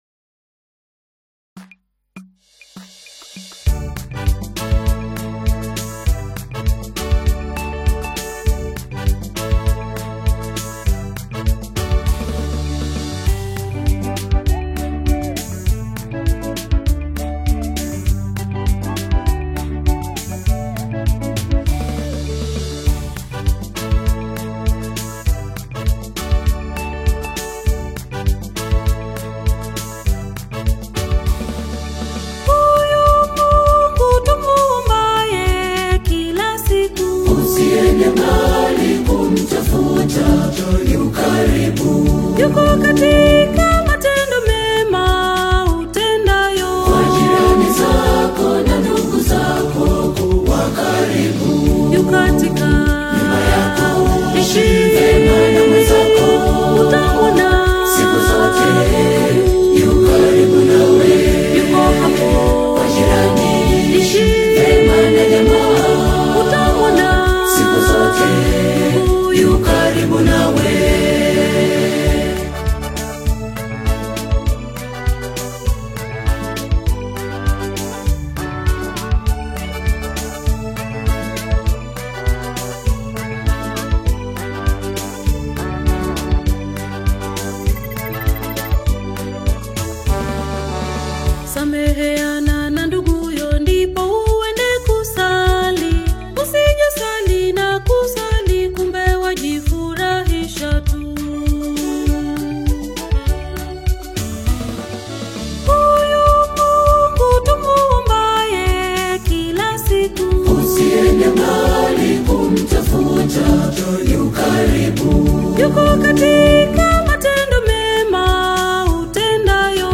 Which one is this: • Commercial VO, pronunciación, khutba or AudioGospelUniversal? AudioGospelUniversal